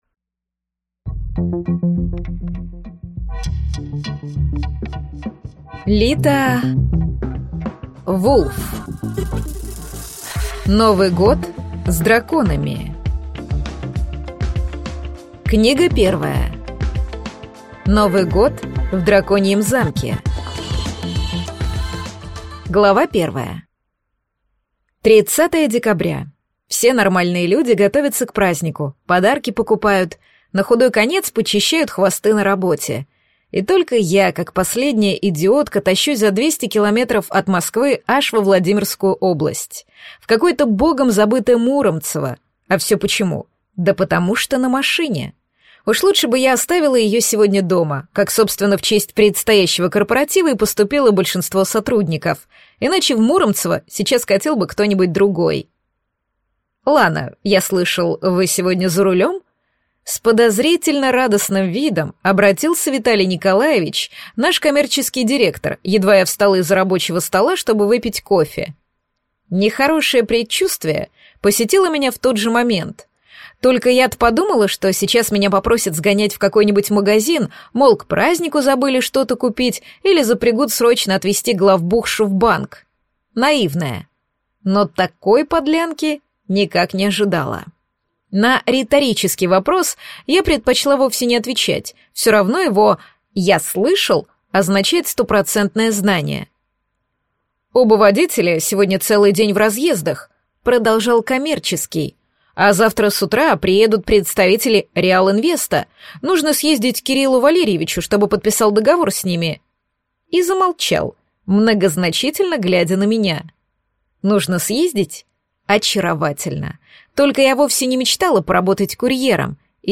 Аудиокнига Новый год в драконьем замке | Библиотека аудиокниг